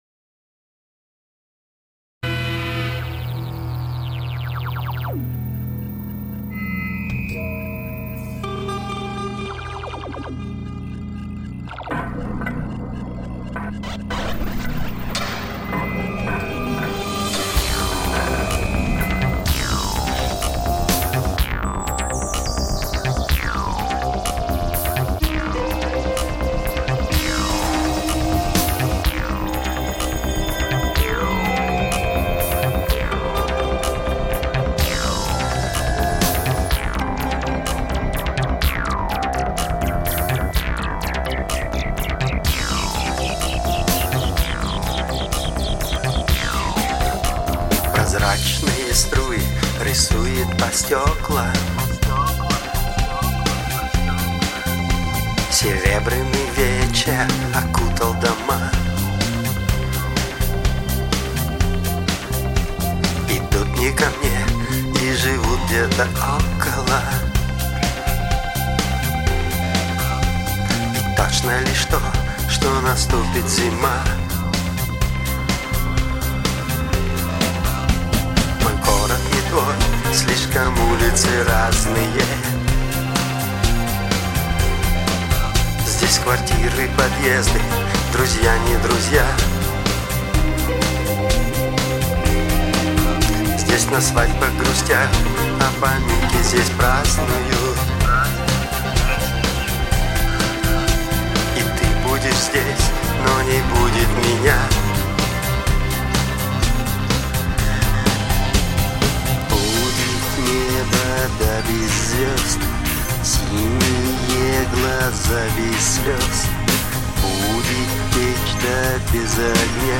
• Жанр: Электронная